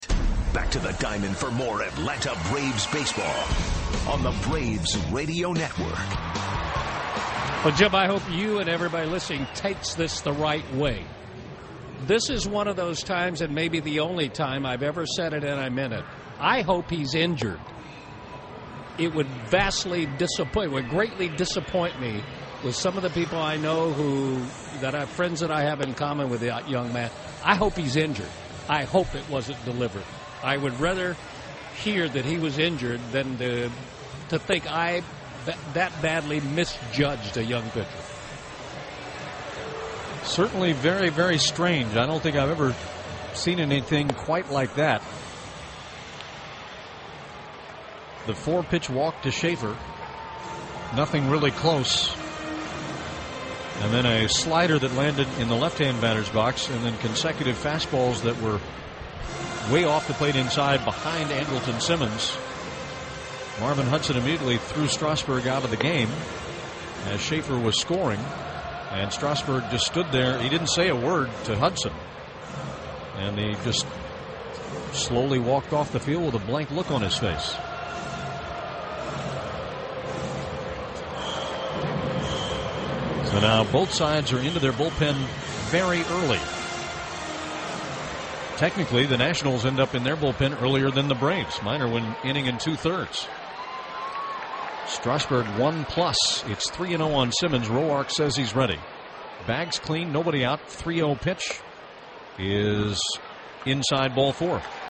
Part 3 of 3, Braves vs. Nationals at Turner Field.